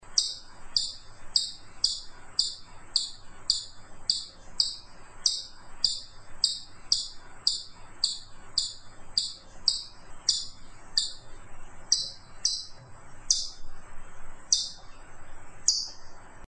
Звуки бурундуков
Ещё писк